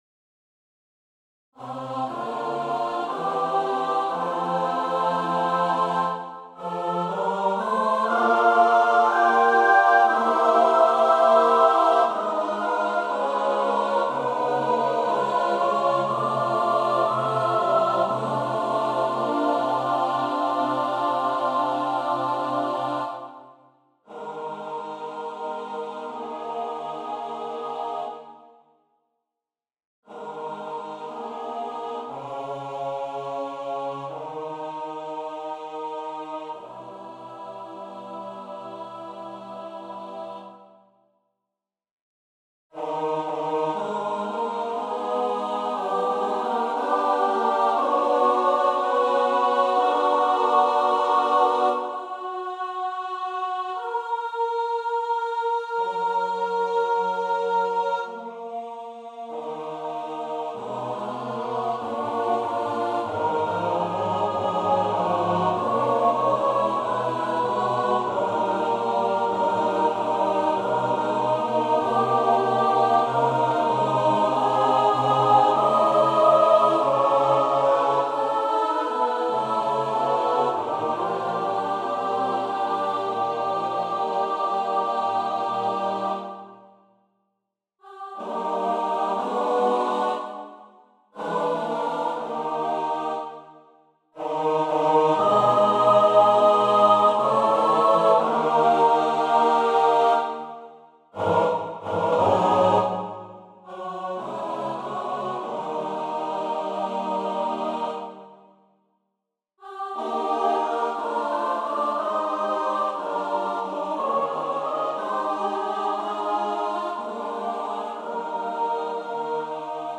Gerard Manley Hopkins Number of voices: 5vv Voicing: SAATB Genre: Secular, Lament
Language: English Instruments: A cappella